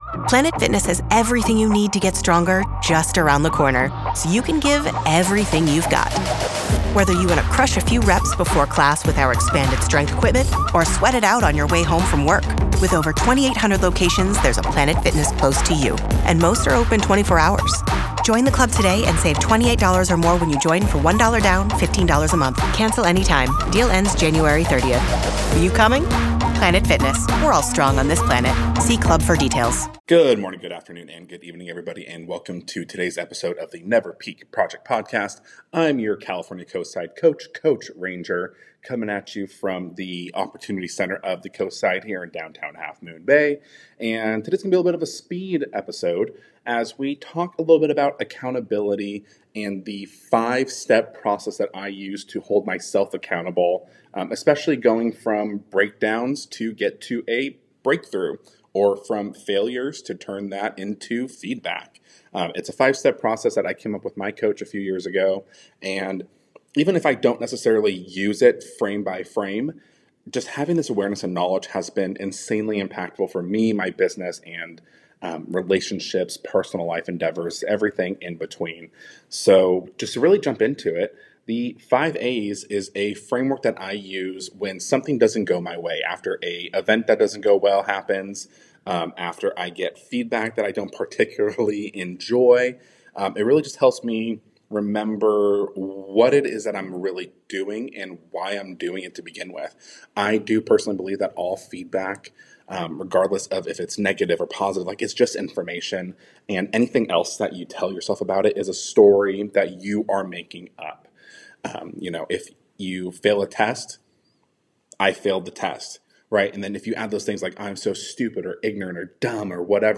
In this quick-hit solo episode